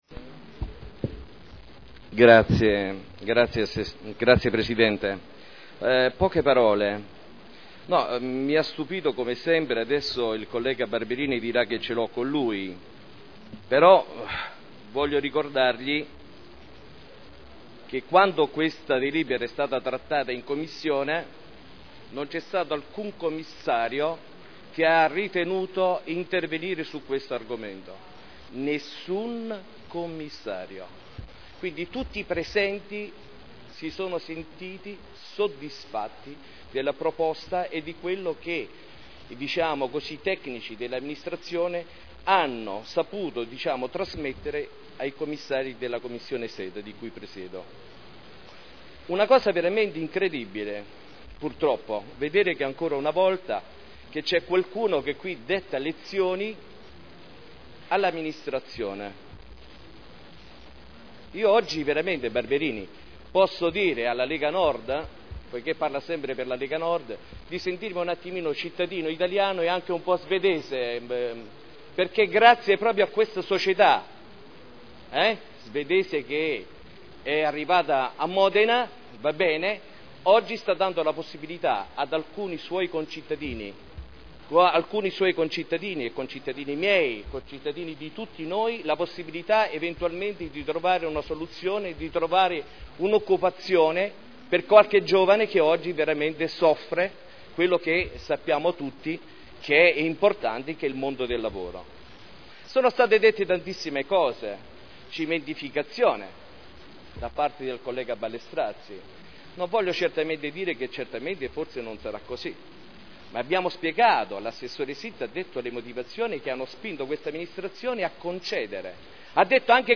Maurizio Dori — Sito Audio Consiglio Comunale